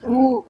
sounds_penguin_02.ogg